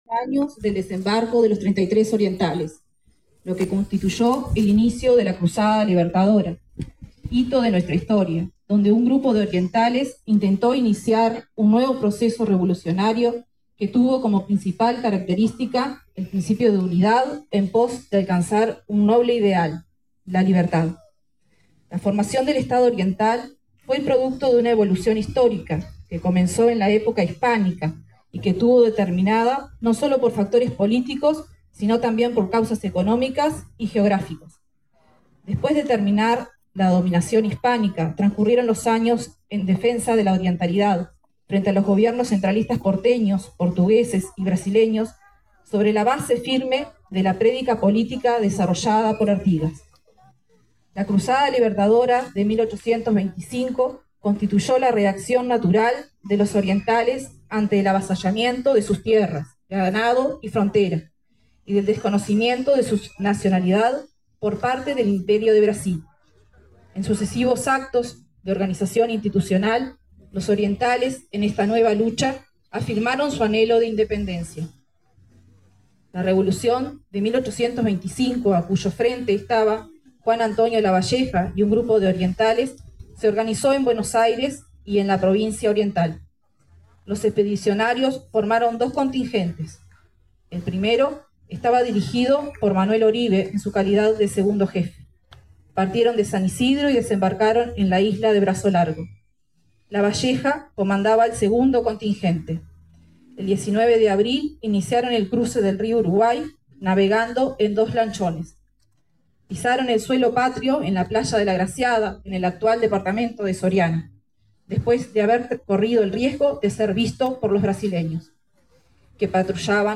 El acto se realizó en la Plaza de los Treinta y Tres de la ciudad de Libertad.
20-4-Acto-por-Desembarco-de-los-Treinta-y-Tres-Orientales.mp3